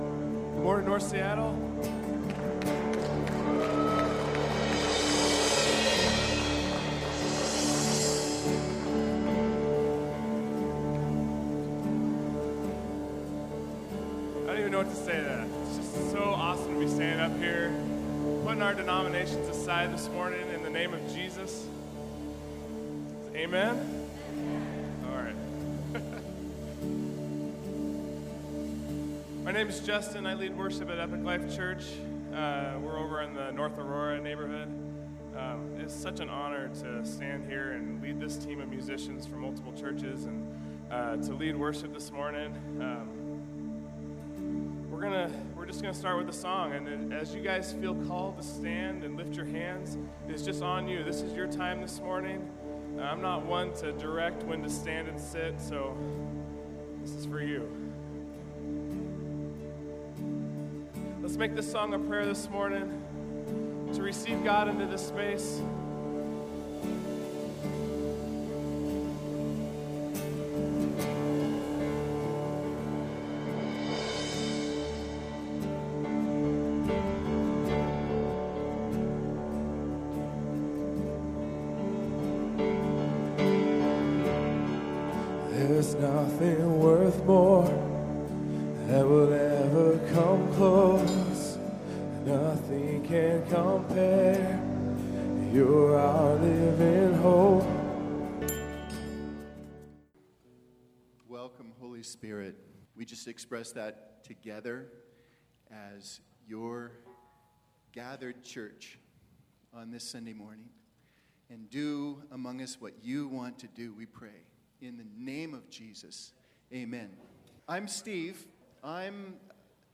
No. Seattle United Worship Service
Over 13 churches from the North Seattle area gathered together for a combined service on August 20.